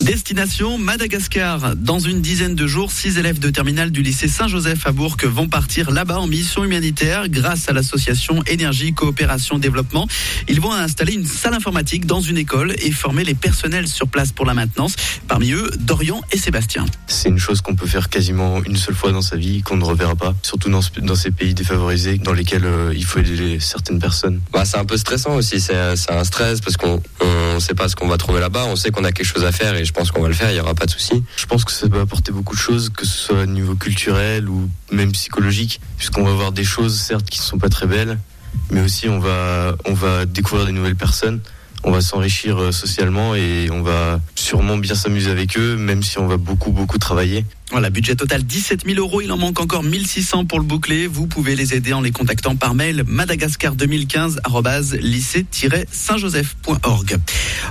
NRJ Bourg en Bresse le 29 Septembre 2015 - Témoignages audio -